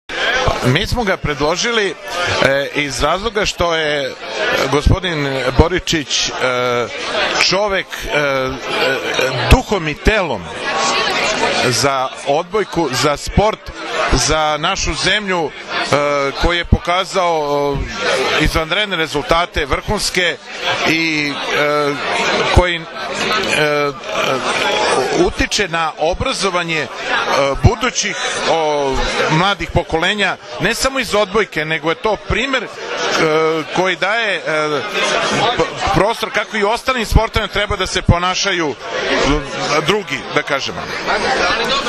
Svečana sednica je održana u amfiteatru Fakulteta, uz prisustvo mnogobrojnih gostiju, studenata i profesora.
IZJAVA